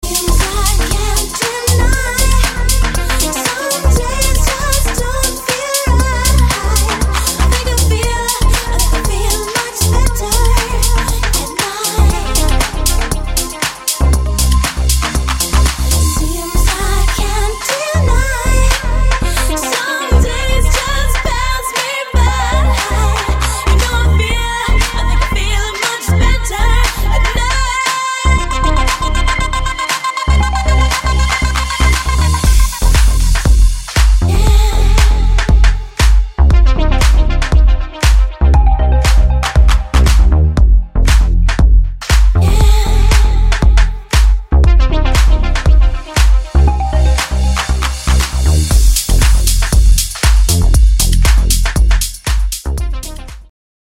женский вокал
dance
club